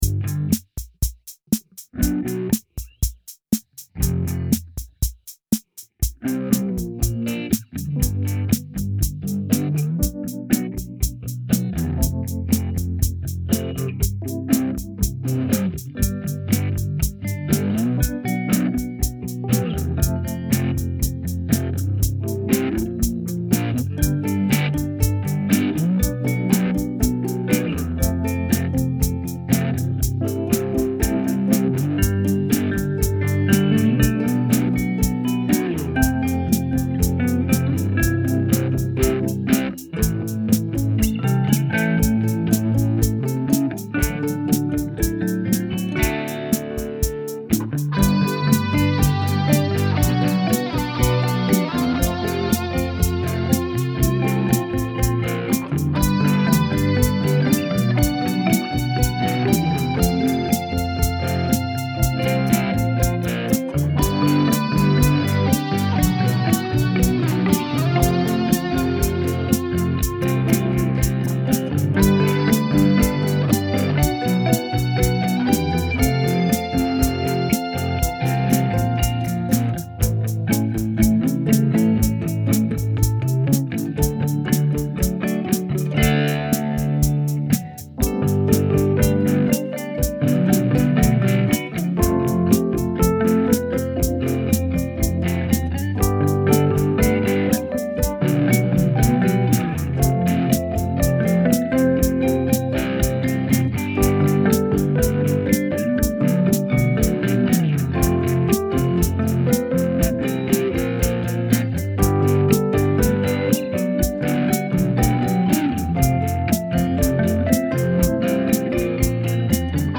Todo empezó con ese ritmo de batería que es puro Casio PT-20. Después sólo hubo que ir sumando instrumentos.
Por cierto, está grabado con la nueva versión del GarageBand que es una maravilla para aquellos que no se quieran complicar demasiado la vida.